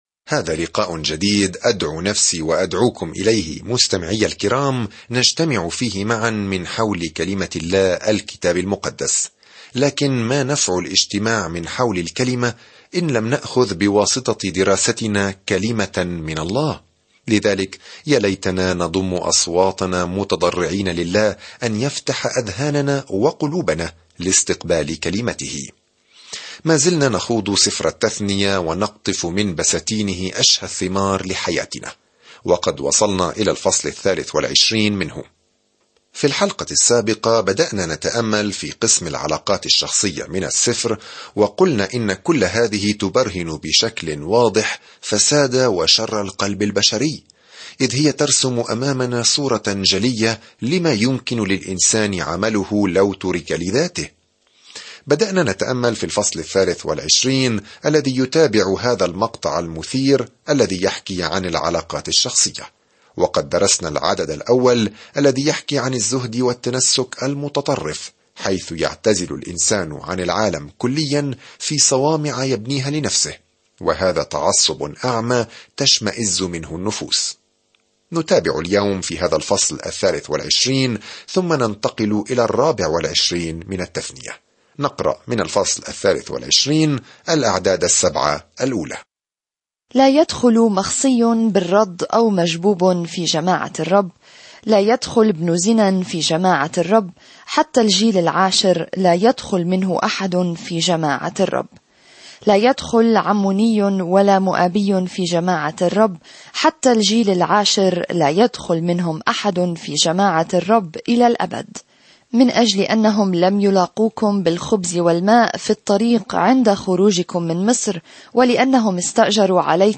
الكلمة اَلتَّثْنِيَة 2:23-25 اَلتَّثْنِيَة 24 اَلتَّثْنِيَة 25 يوم 14 ابدأ هذه الخطة يوم 16 عن هذه الخطة يلخص سفر التثنية شريعة الله الصالحة ويعلمنا أن الطاعة هي استجابتنا لمحبته. سافر يوميًا عبر سفر التثنية وأنت تستمع إلى الدراسة الصوتية وتقرأ آيات مختارة من كلمة الله.